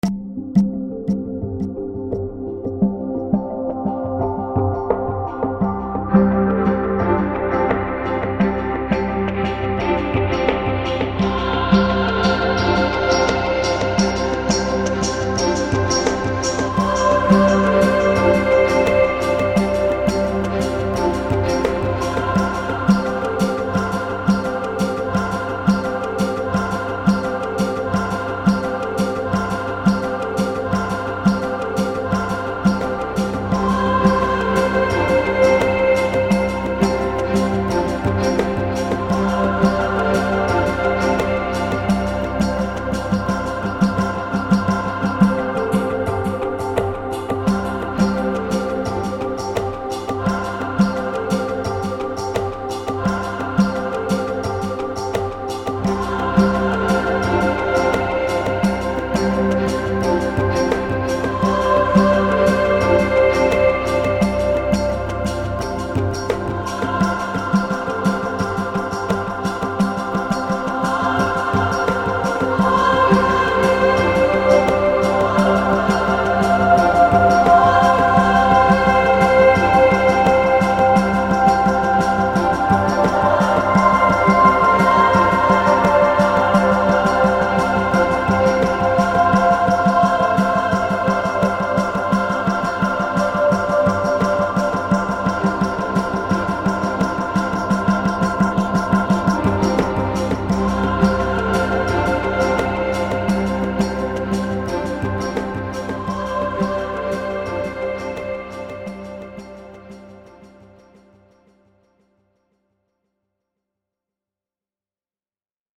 A very sweet, and beautiful track made in GARAGEBAND!!.
Game Music
epic